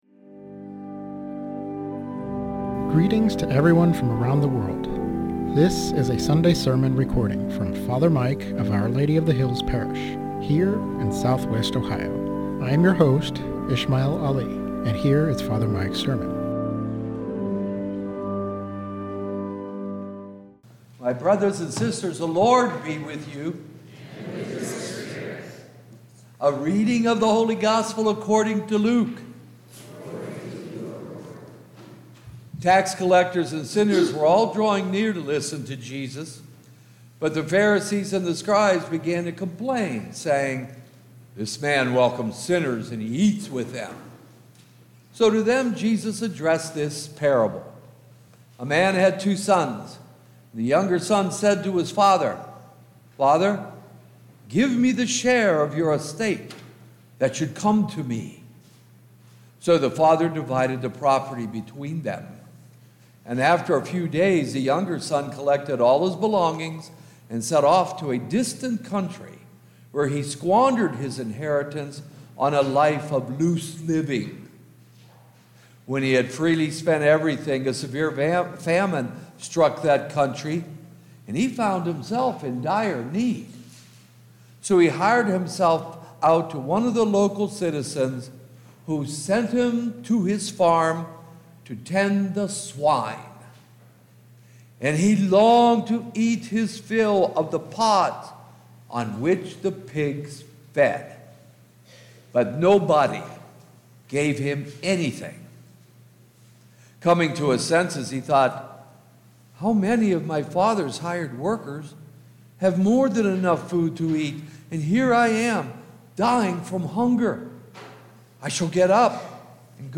Sermon on the Prodigal Son and the Merciful Father - Our Lady of the Hills - Church